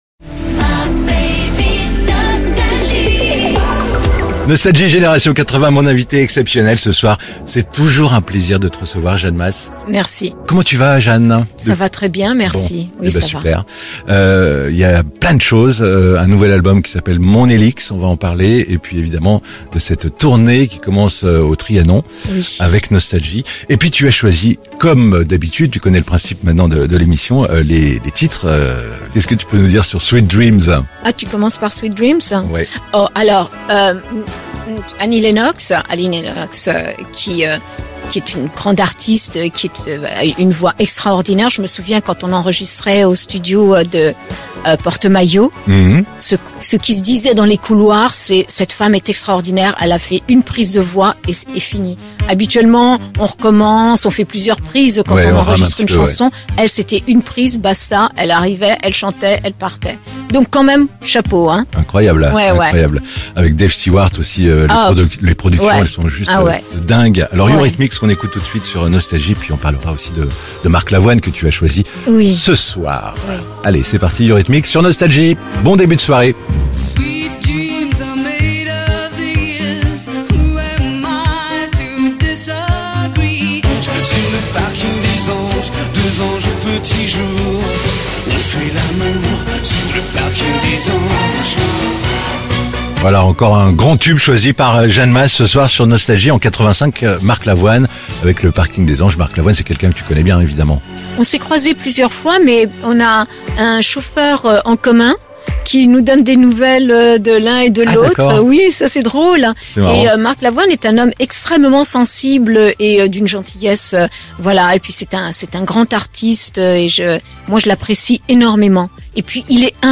Jeanne Mas partage sa playlist idéale sur Nostalgie ! ~ Les interviews Podcast